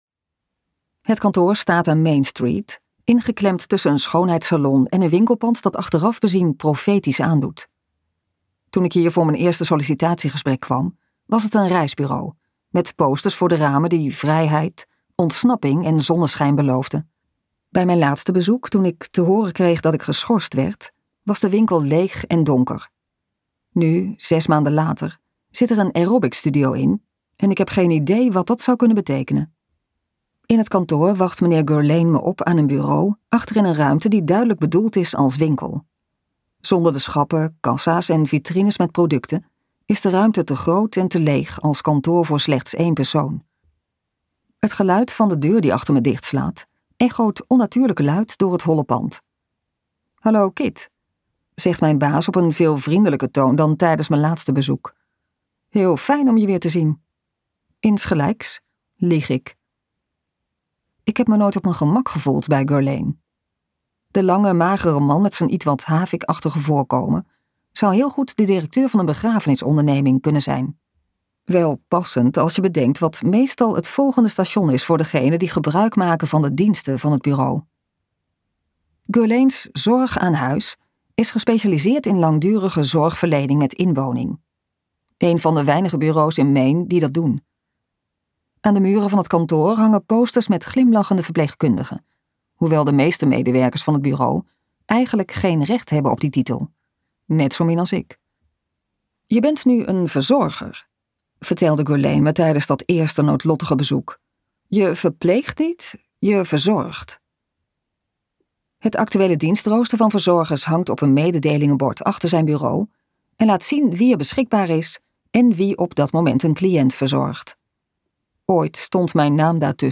Ambo|Anthos uitgevers - De enige die overbleef luisterboek